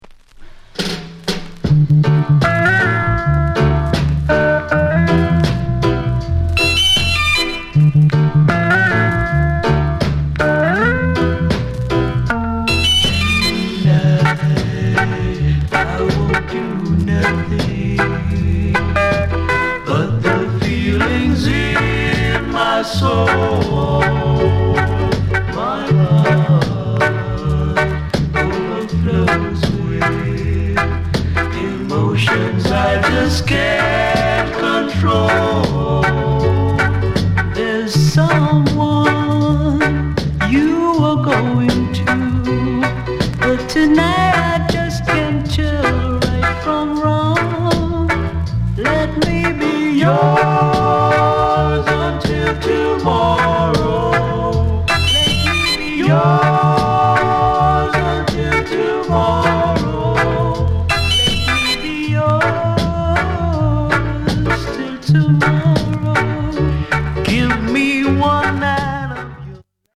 SOUND CONDITION VG
ROCKSTEADY